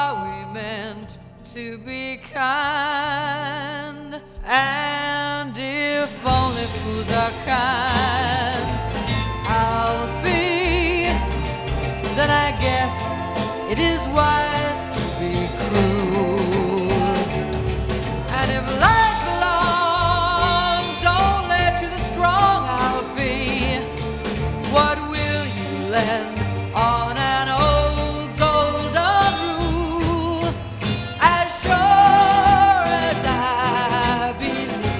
Жанр: Поп / Рок / Фолк-рок